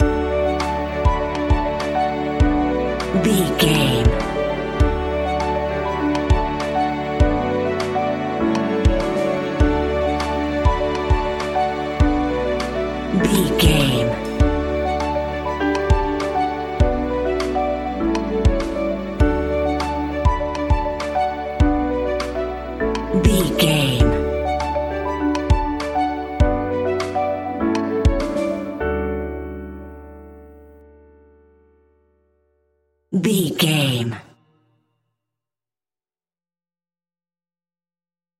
Ionian/Major
D
pop rock
indie pop
energetic
uplifting
instrumentals
upbeat
guitars
drums